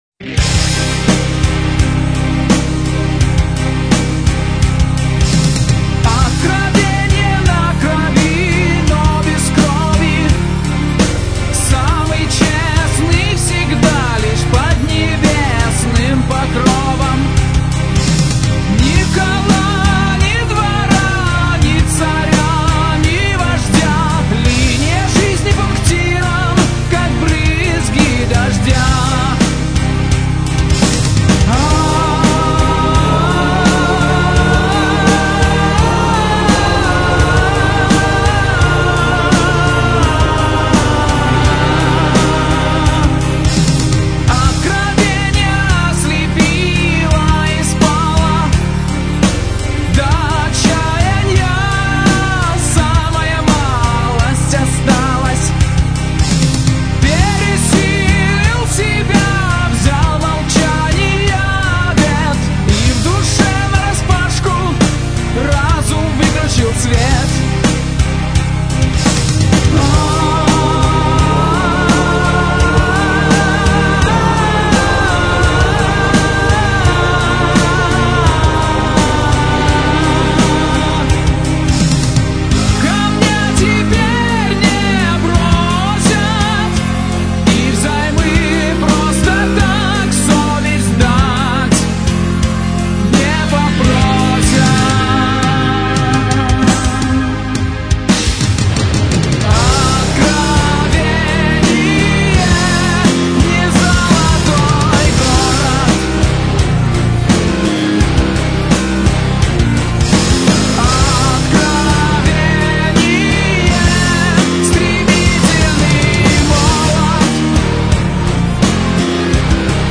Metal
гитара